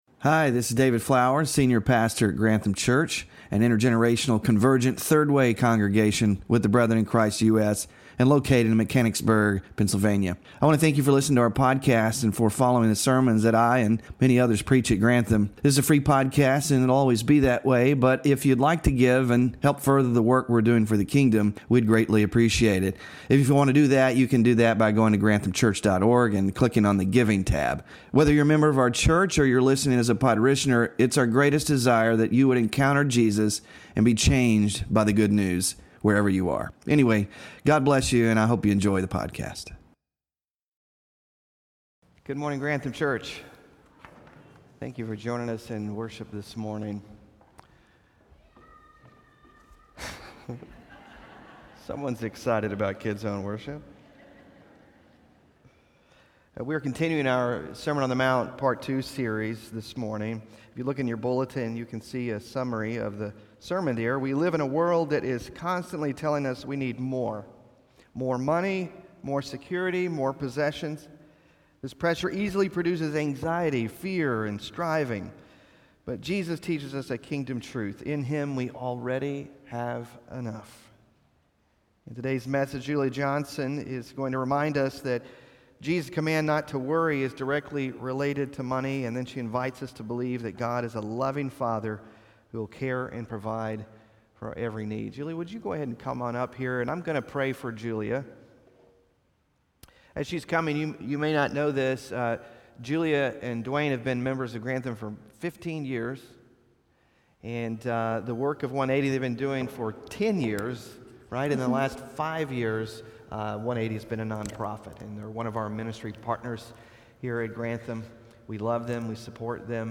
Sermon Focus: We live in a world that is constantly telling us we need more—more money, more security, more possessions.